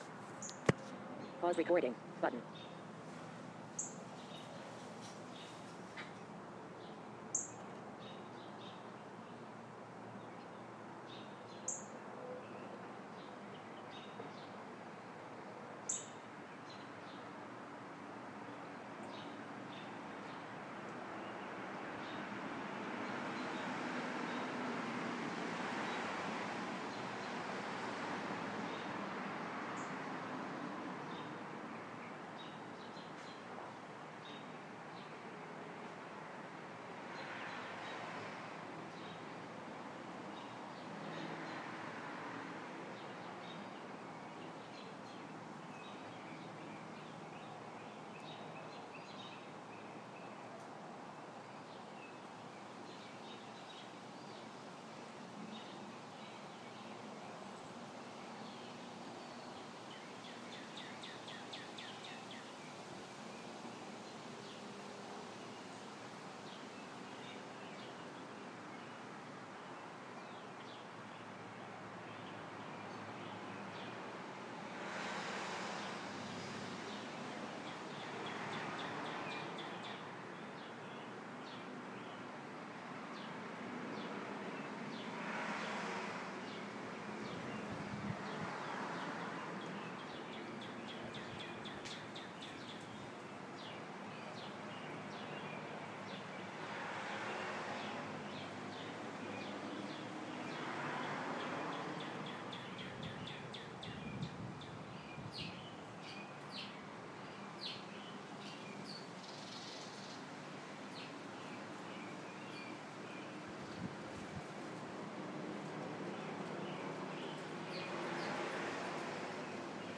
Birds in my feeder